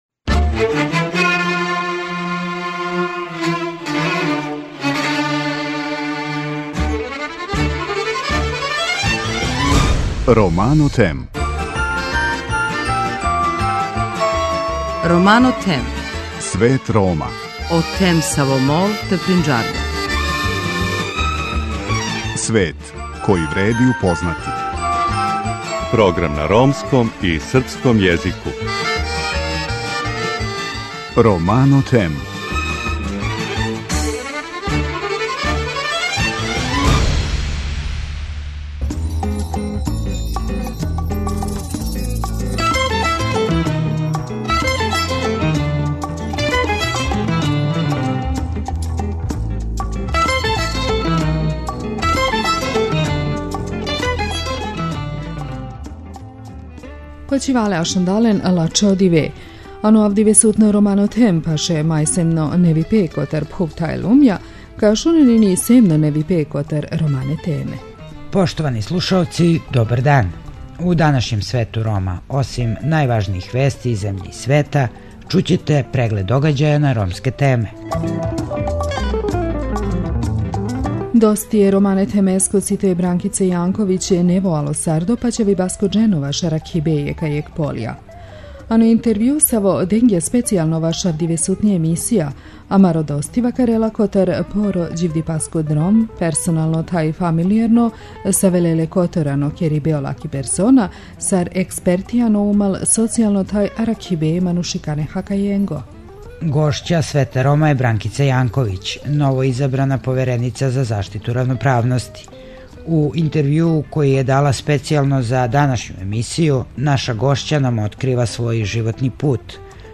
Гошћа Света Рома је Бранкица Јанковић, новоизабрана Повереница за заштиту равноправности.